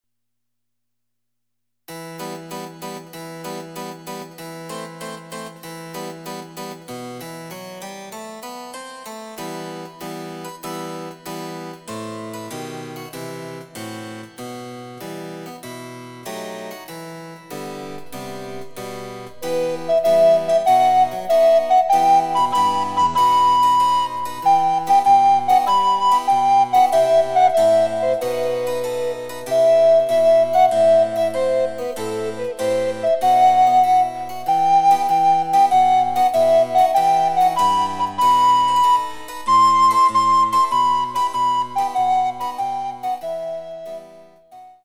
チェンバロ伴奏で楽しむ日本のオールディーズ、第６弾！
※伴奏はモダンピッチのみ。